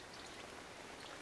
ihan kuin luhtahuitin ääni?
Noin 200 metriä on ison lintulahden rantaan.
ihan_kuin_luhtahuitin_aani_tama_on_noin_200m_ison_lintulahden_rannasta.mp3